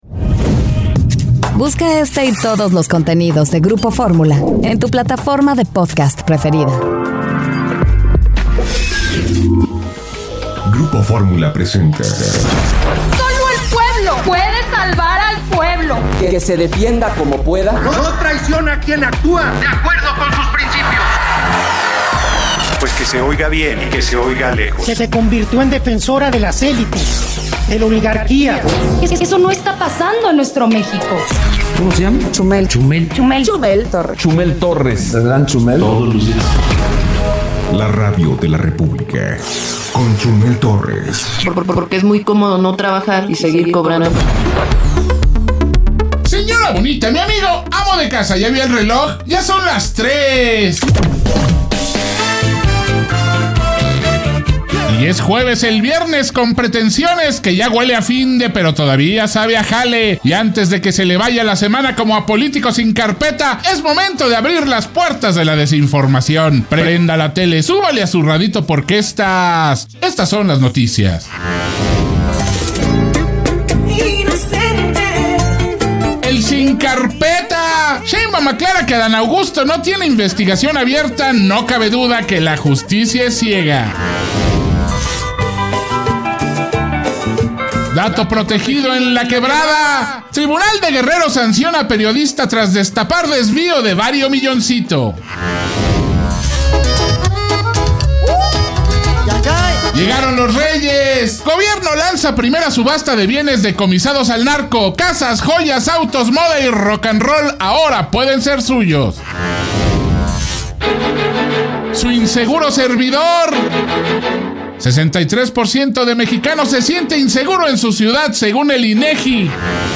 Aquí está la Radio de la República, el peor noticiero con su tuitero favorito.